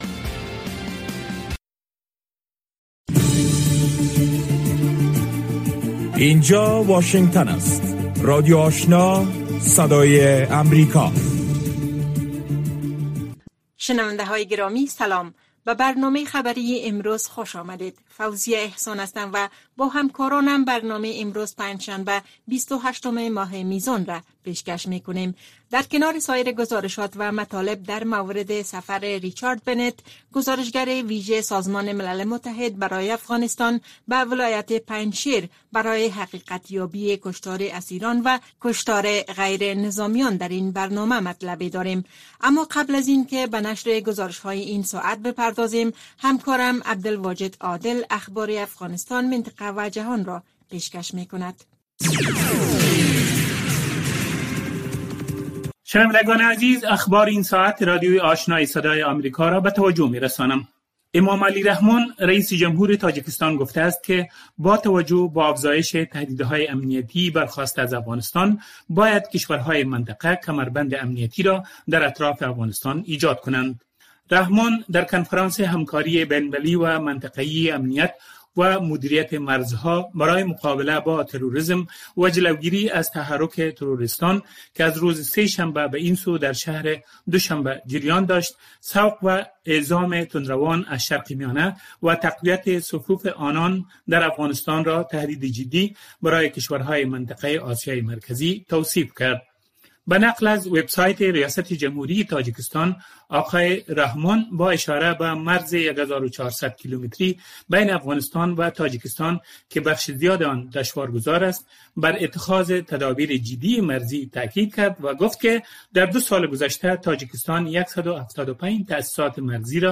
برنامۀ خبری صبحگاهی